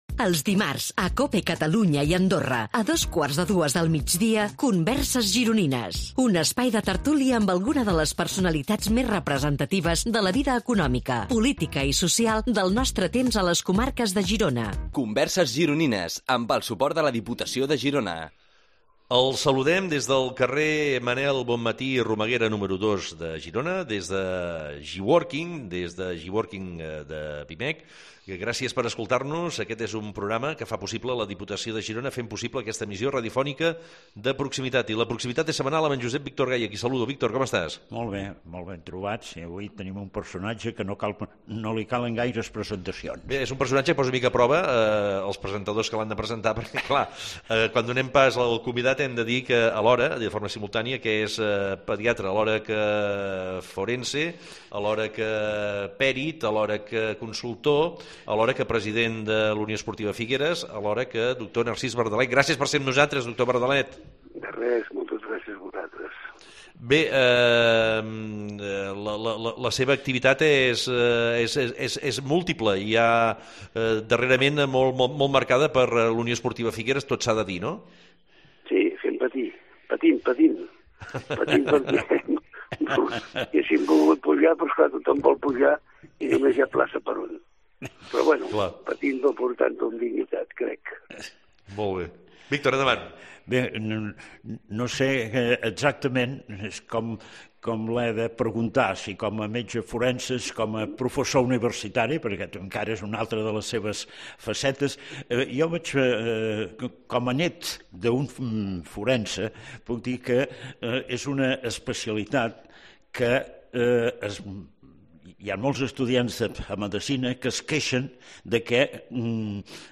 A “Converses Gironines” entrevistem algunes de les grans personalitats rellevants de la vida política, econòmica cultural o social de Girona.
Aquestes converses es creen en un format de tertúlia en el que en un clima distès i relaxat els convidats ens sorprenen pels seus coneixements i pel relat de les seves trajectòries.